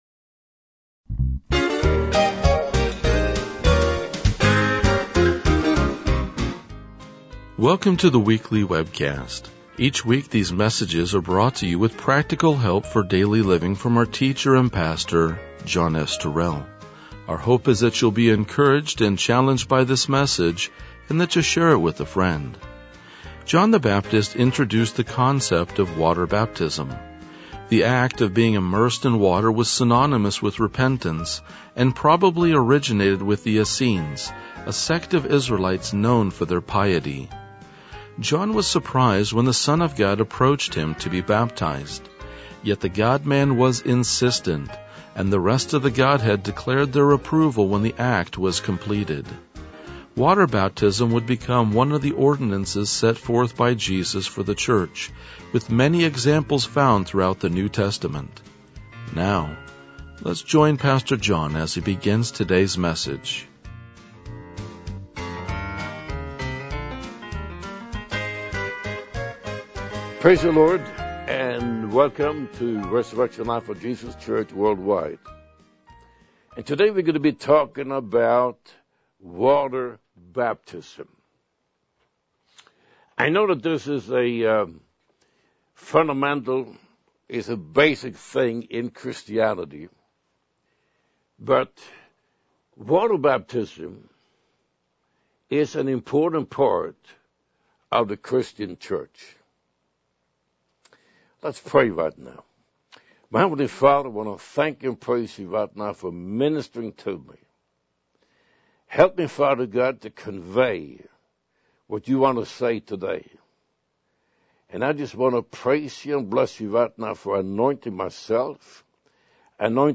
RLJ-2014-Sermon.mp3